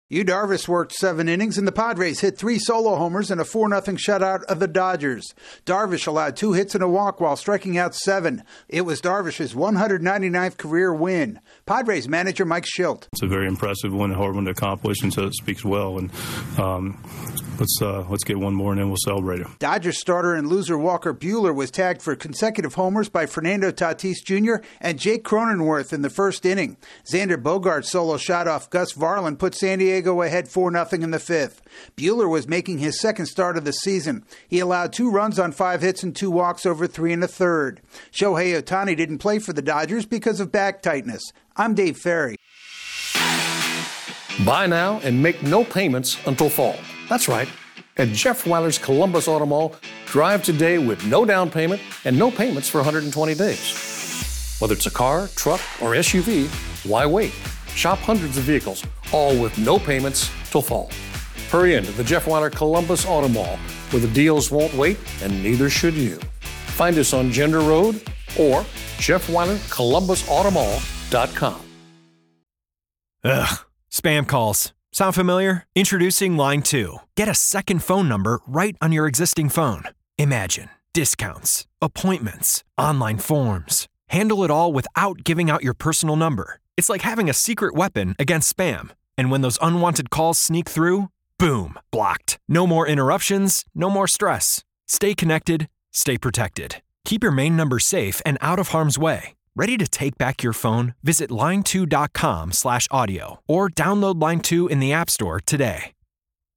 The Padres beat the Dodgers for the second straight game. AP correspondent